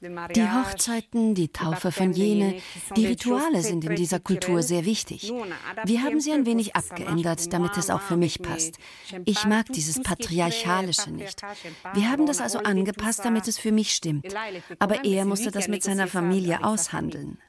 voice over-srf-schweizer liebesgeschichten-nr.2